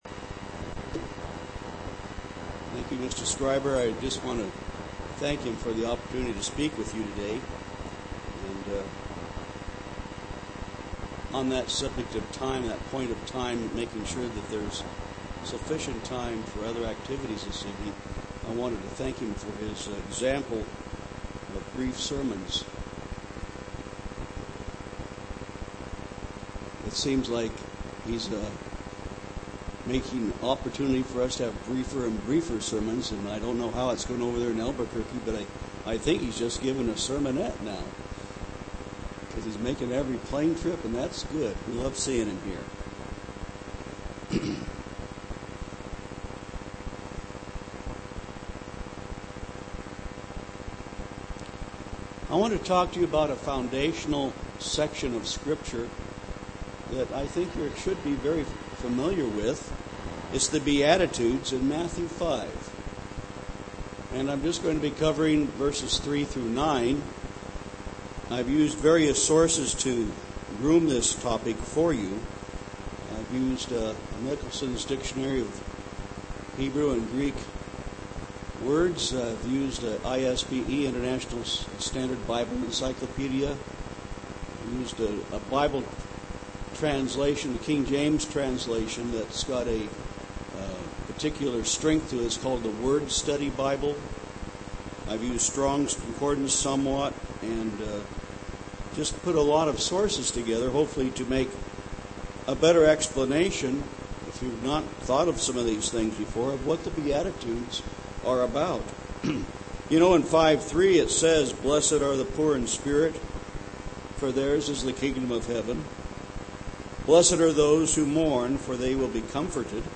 UCG Sermon Christian life christian love Studying the bible?